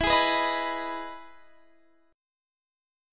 Listen to EM7 strummed